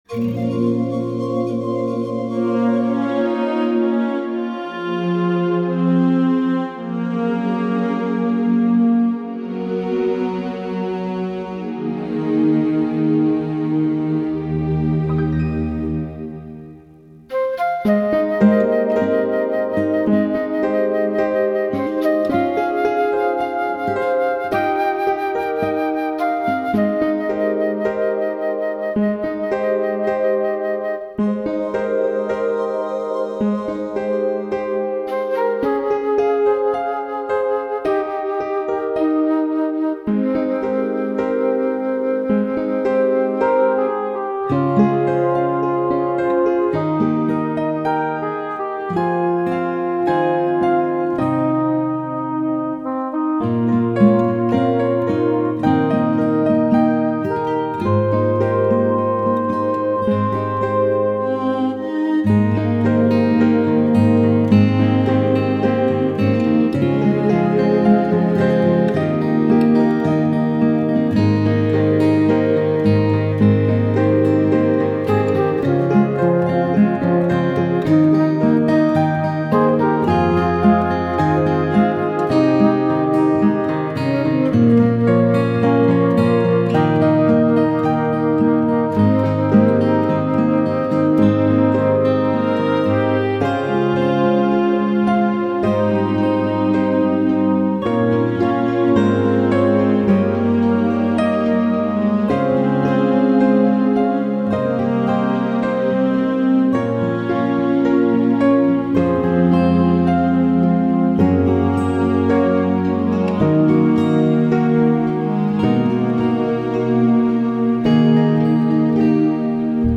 This medley of lush song arrangements convey peacefulness.